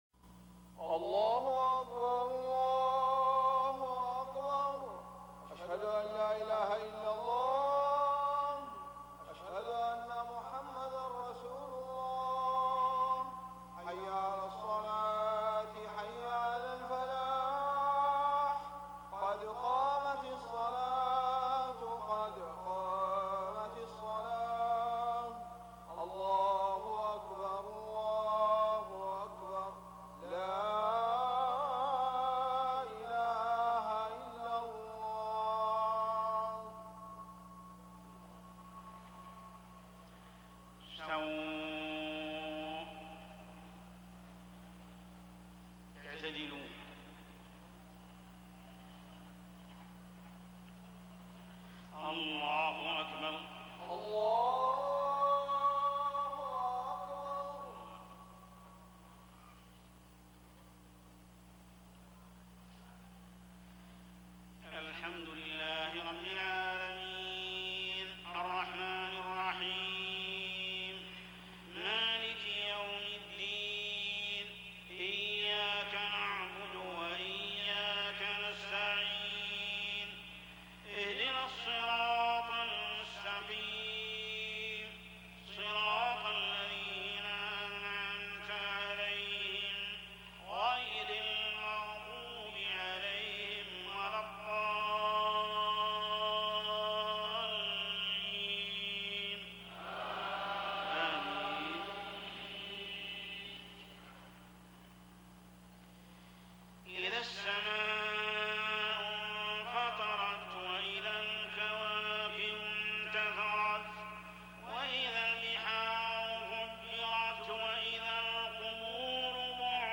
صلاة العشاء عام 1420هـ سورة الانفطار > 1420 🕋 > الفروض - تلاوات الحرمين